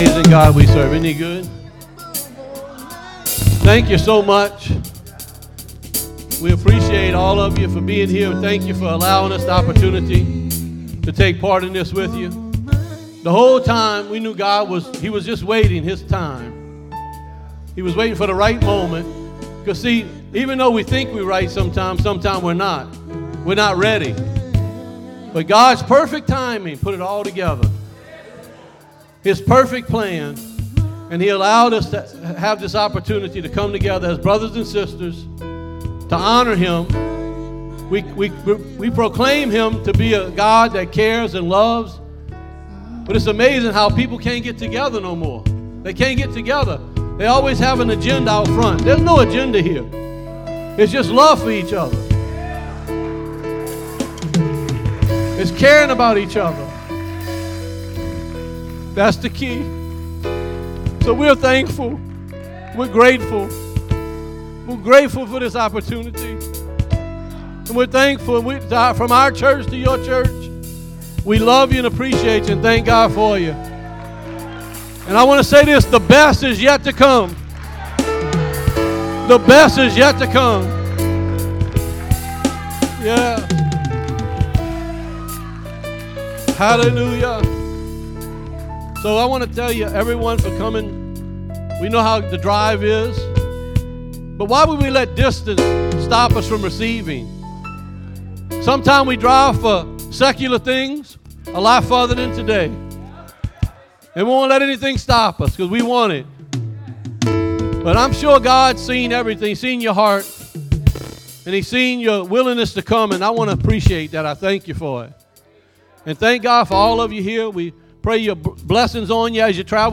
by | posted in: Saturday, Service Recordings | 1
It was also Family and Friends Day.